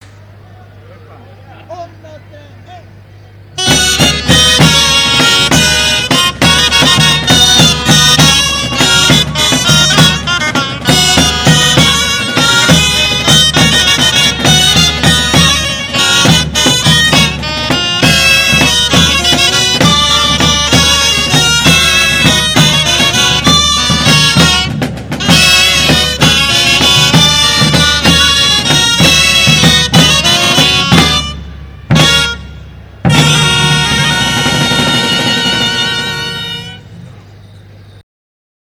19.2 BALL DE GITANES DE TARRAGONA BALL Grallers Tocaferro